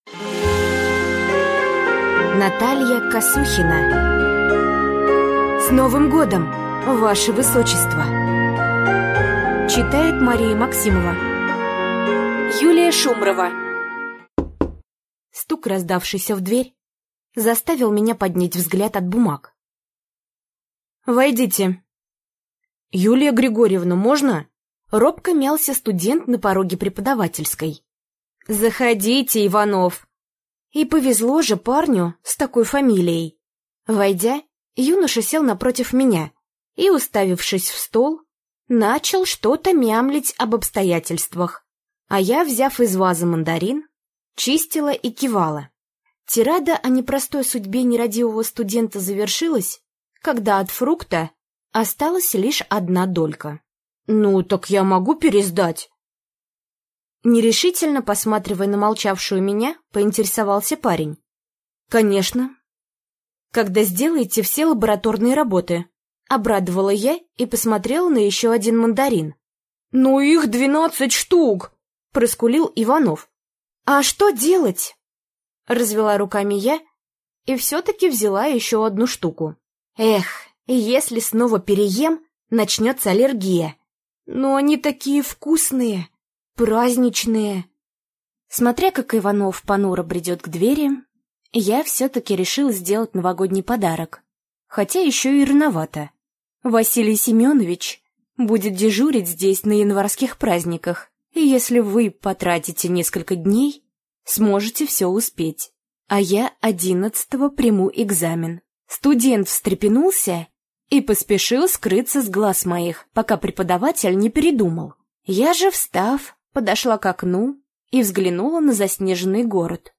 Аудиокнига С Новым годом! Ваше высочество!